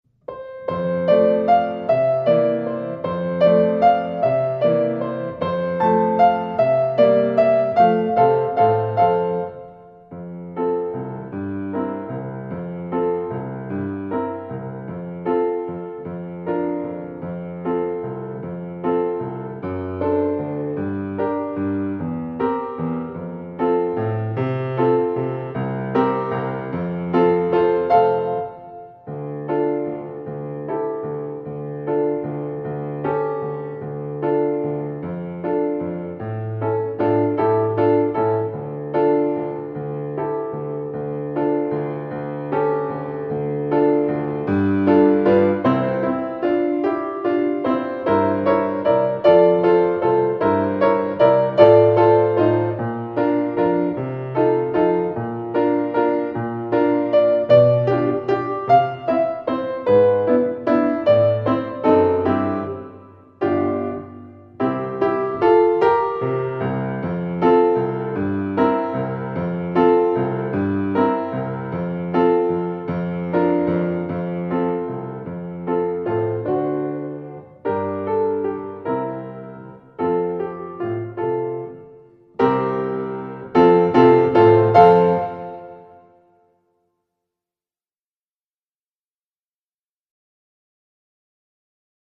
accompagnement seul